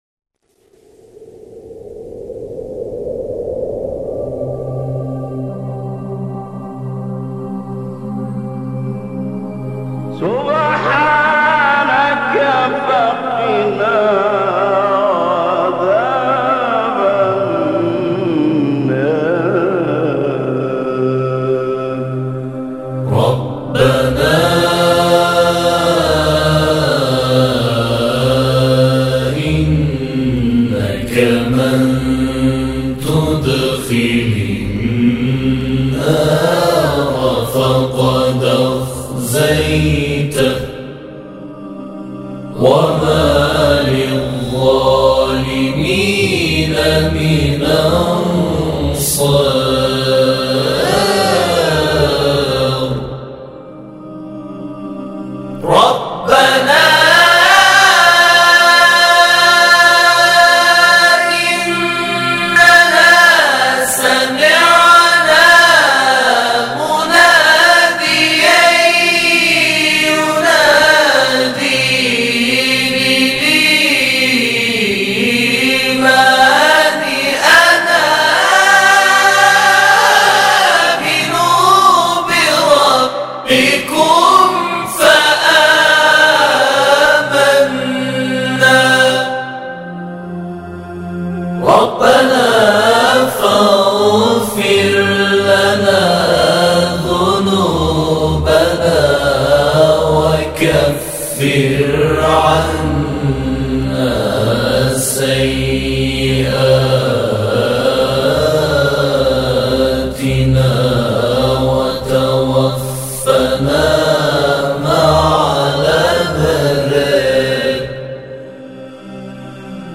در مسجد تاریخی جامع اصفهان همخوانی و اجرا شده است
تلاوت گروهی